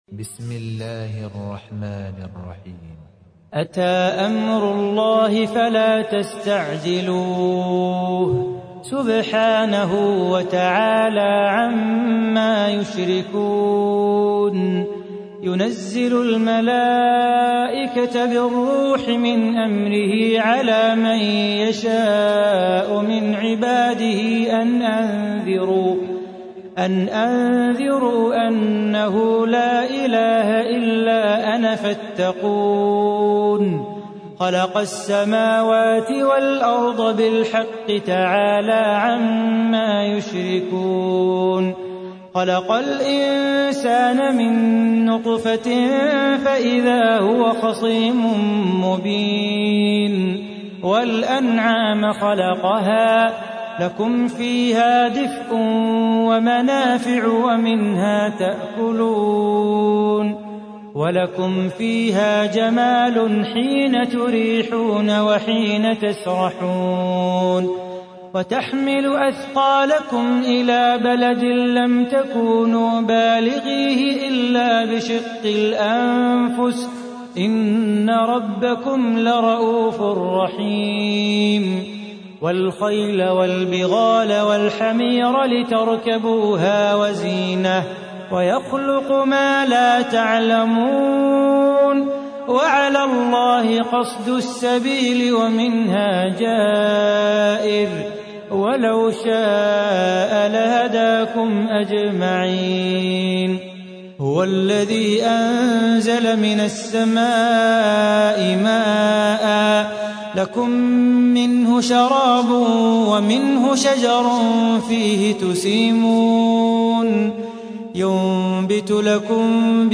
تحميل : 16. سورة النحل / القارئ صلاح بو خاطر / القرآن الكريم / موقع يا حسين